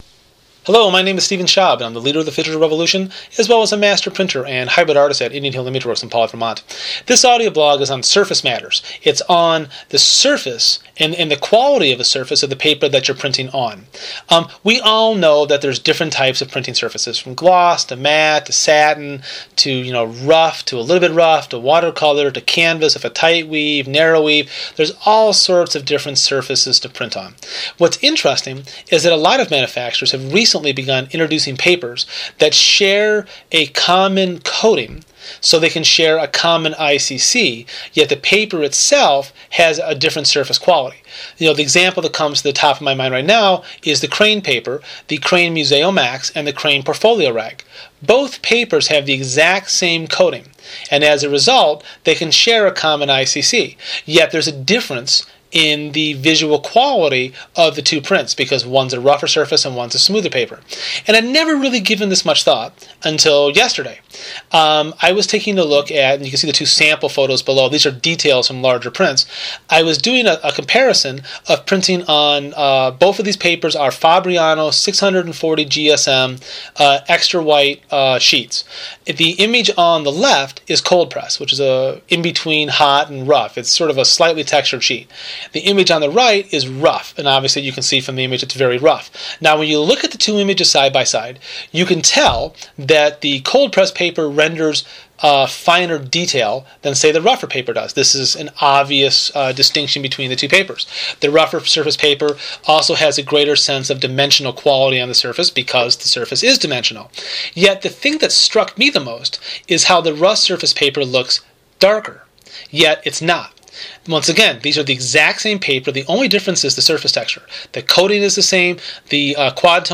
In this audioblog I present a philosophy of scanning film based on the understanding that in the end photographers make prints and our entire process from film capture to scan to Photoshop to printer is all based on making prints. I outline in detail techniques and settings which will apply to most scanners and will help you get the most of your film and scanner combination. This is a very long audioblog- 27 minutes- and as such have paper and pencil ready, as you won’t want to listen to my voice for this long twice!